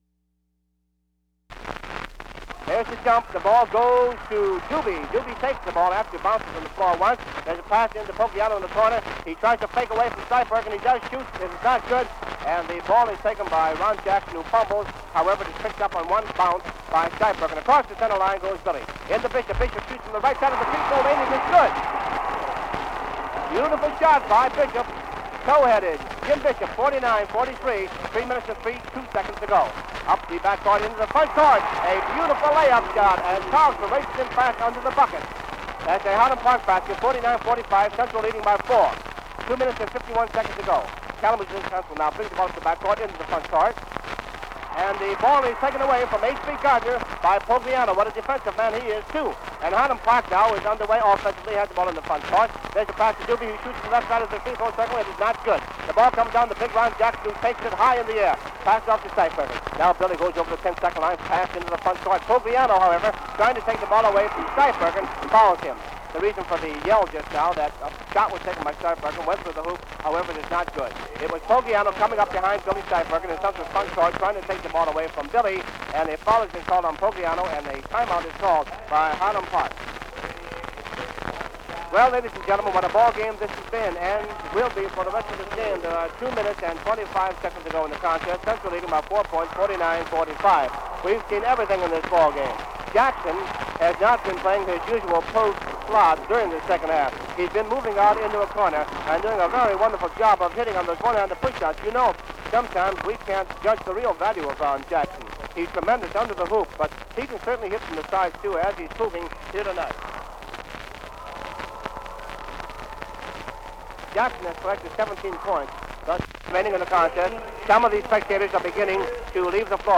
An unknown announcer covers the last half of the Class A Michigan High School Athletic Association (MHSAA) Boys Basketball championship game at Michigan State University's Jenison Field House in East Lansing, Michigan. Kalamazoo Central High School defeats Highland Park by a score of 50-47 and becomes the first school in MHSAA history to win three consecutive Class A state tournament boys basketball championships.
Broadcast 1951 March 17.